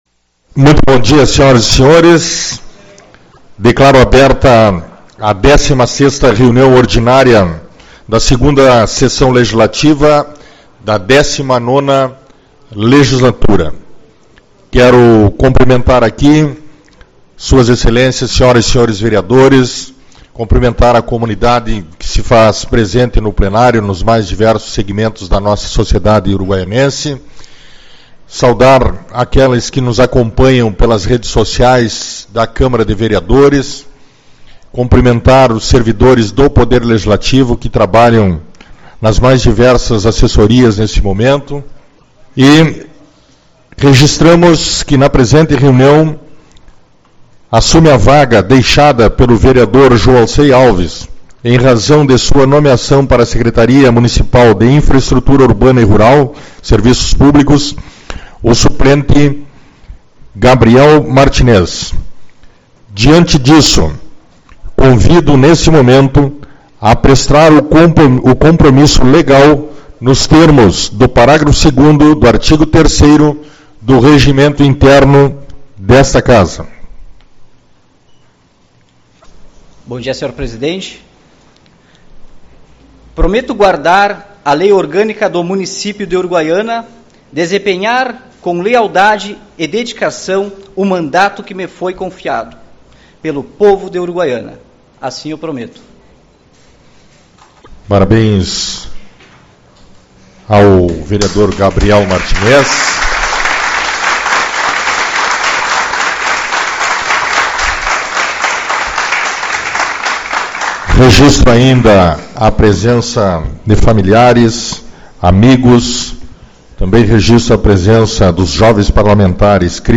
02/04 - Reunião Ordinária